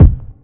Headquarters Kick.wav